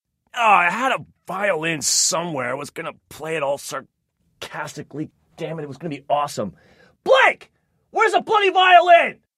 Sarcastic Violin1